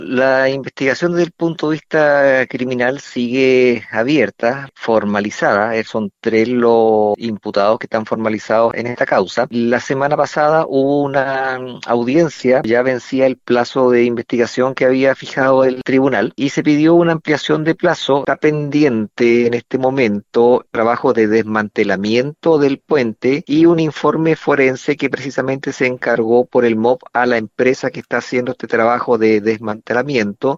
En conversación con Radio Sago, el fiscal del Sistema de Análisis Criminal y Focos Investigativos, Naín Lamas explicó cómo va la investigación sobre la caída del puente Cancura.